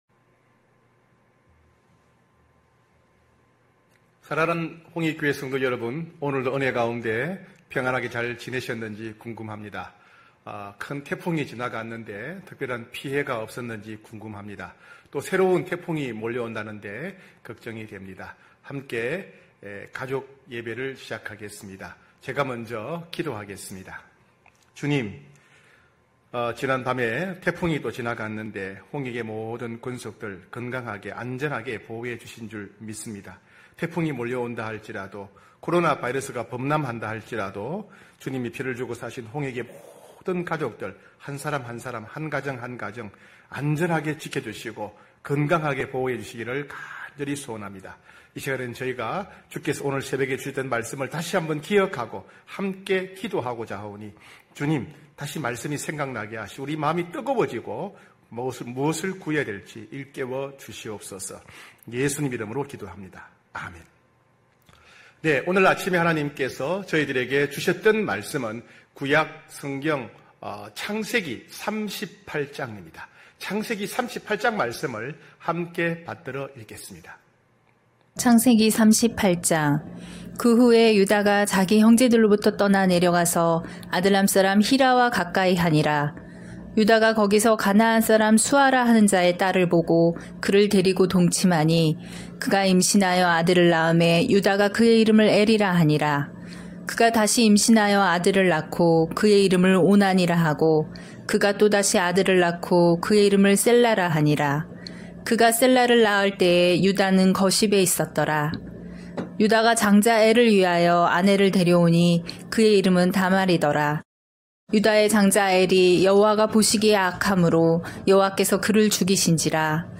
9시홍익가족예배(9월3일).mp3